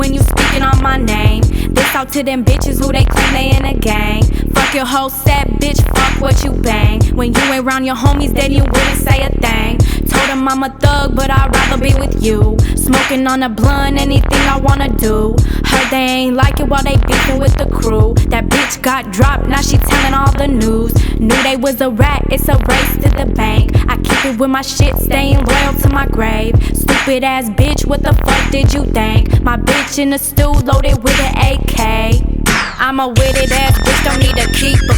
Жанр: Хип-Хоп / Рэп / Поп музыка
Hip-Hop, Rap, Pop